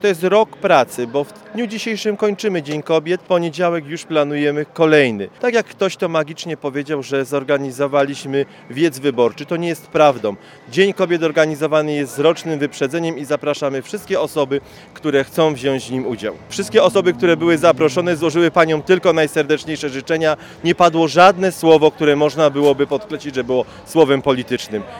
Kwiaty, muzyka i radosna atmosfera